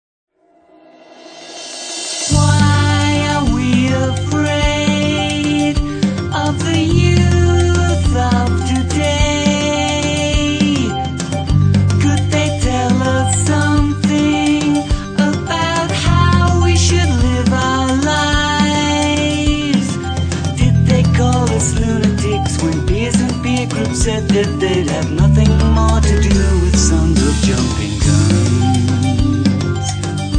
quirky idiosyncratic numbers